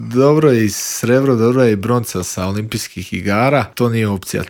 O svom putu do svjetske bronce govorio je naš najbolji boksač u Intervjuu Media servisa.